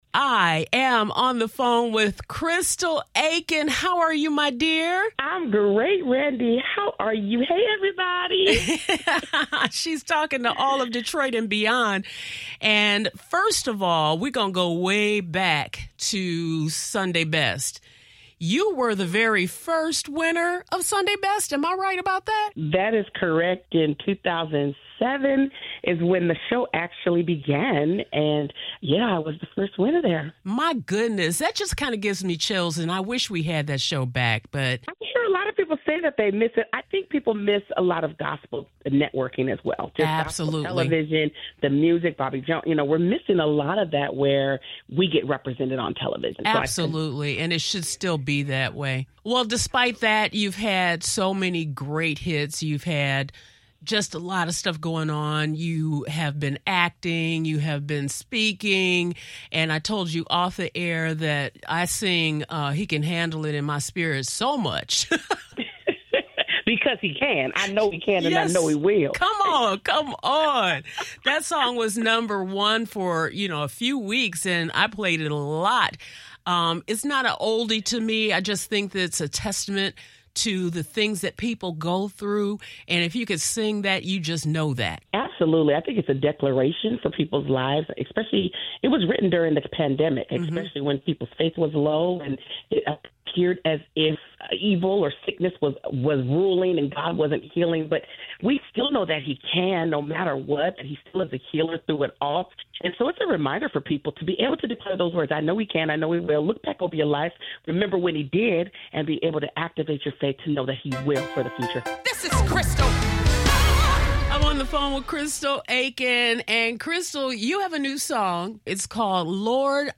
Crystal Aikin’s voice has always carried both power and peace — a gift that first captured hearts when she became the inaugural winner of BET’s Sunday Best back in 2007.
Her new single, “Lord, I Need Your Help,” is a personal cry that reminds us who we turn to when life feels heavy. During our conversation, Crystal shared that the song came from a very real place.